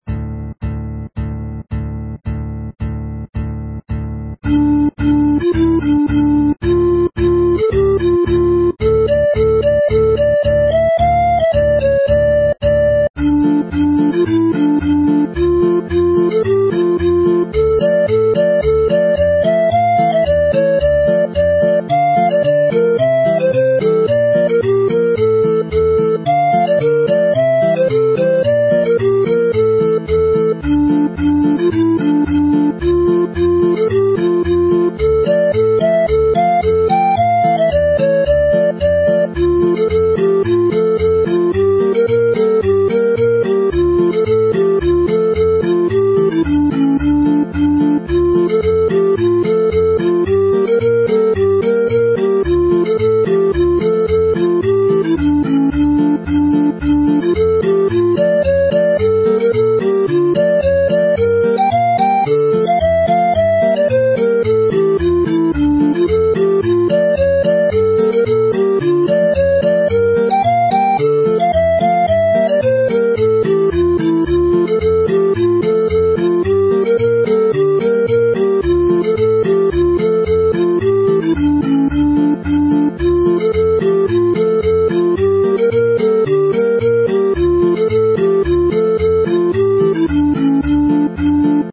Morwyn y Pentre (the Village Maid) is suited superbly to the wooden flute